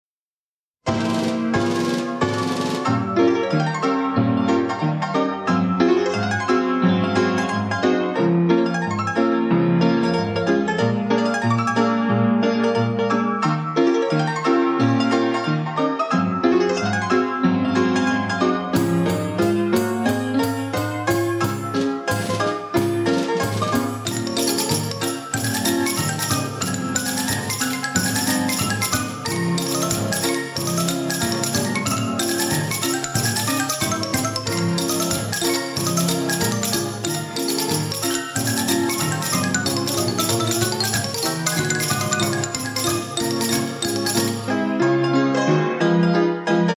Beautiful music played on a 1920's Nelson Wiggen Style 6.